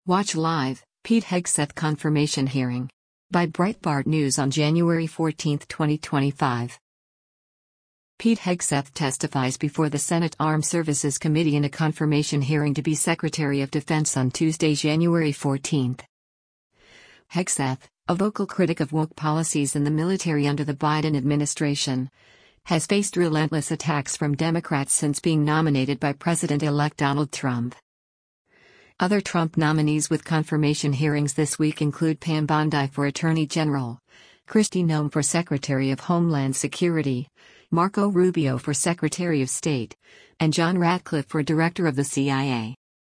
Pete Hegseth testifies before the Senate Armed Services committee in a confirmation hearing to be Secretary of Defense on Tuesday, January 14.